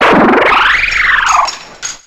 KLINKLANG.ogg